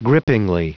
Prononciation du mot grippingly en anglais (fichier audio)
Prononciation du mot : grippingly